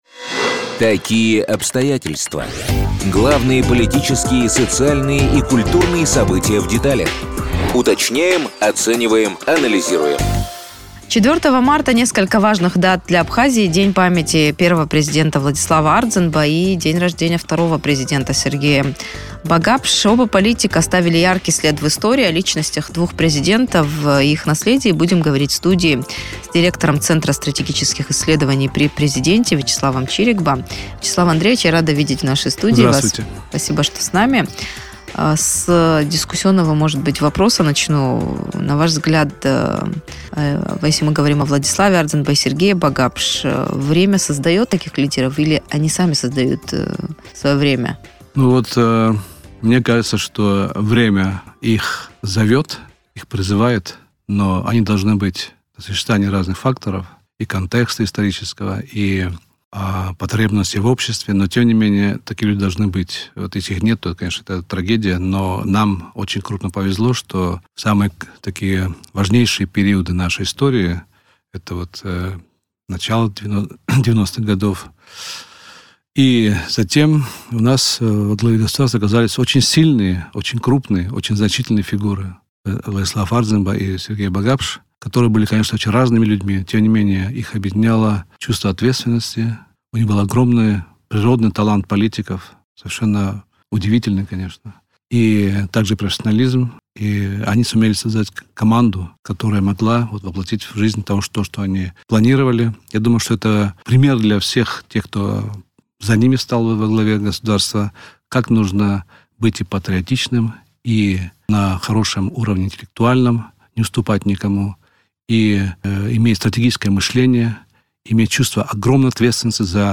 О том, какой след в истории Абхазии оставили Владислав Ардзинба и Сергей Багапш, в интервью радио Sputnik рассказал директор Центра стратегических исследований при президенте Вячеслав Чирикба.